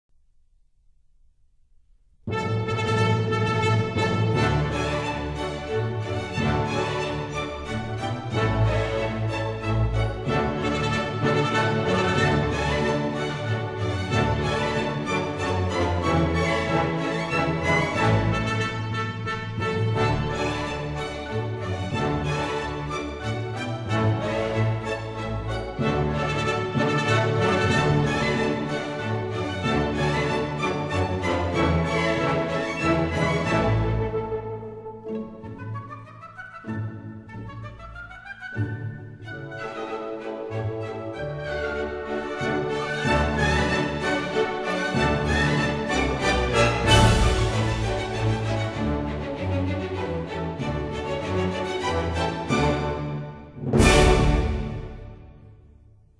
Polacca
波拉卡舞曲<00:56>
Sorry, 论坛限制只许上传10兆一下，而且现在激动的网速巨慢，所以只能牺牲品质，为大家介绍一下音乐了。